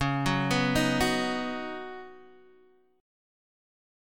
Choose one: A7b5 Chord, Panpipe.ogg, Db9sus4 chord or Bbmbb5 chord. Db9sus4 chord